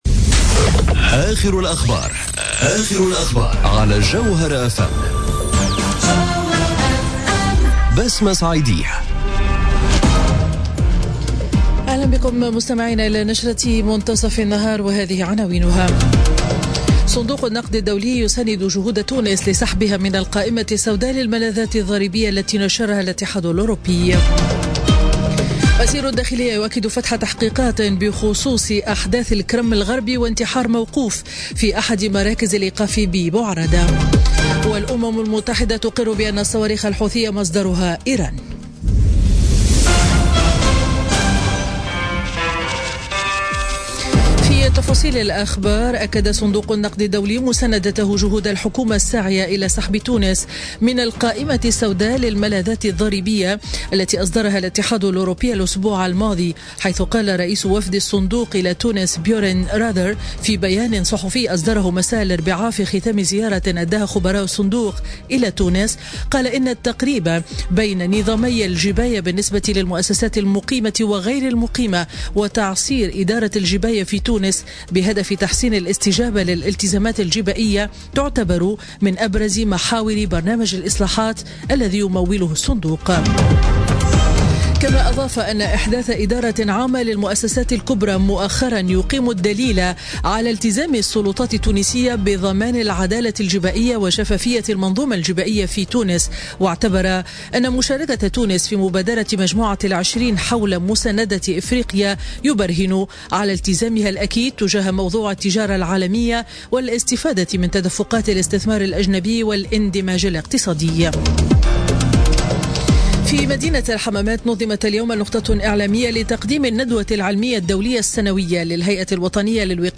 نشرة أخبار منتصف النهار ليوم الخميس 14 ديسمبر 2017